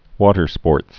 (wôtər-spôrts, wŏtər-)